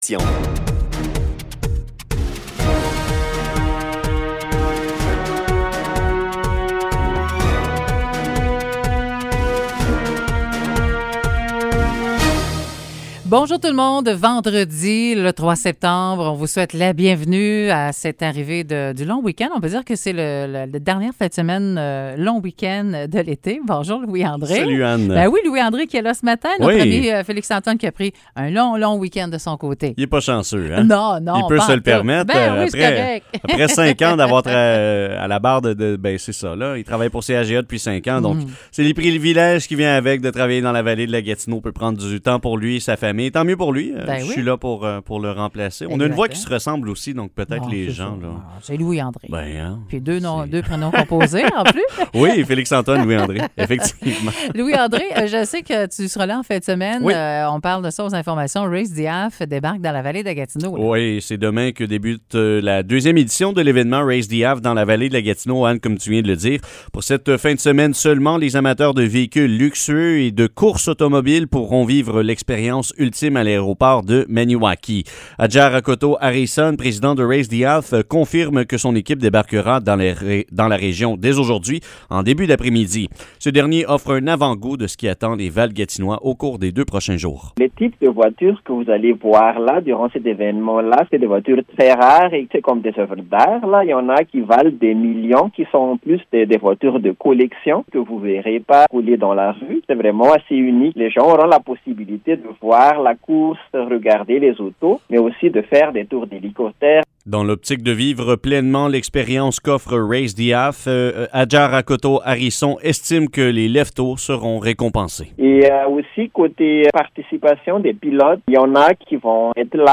Nouvelles locales - 3 septembre 2021 - 9 h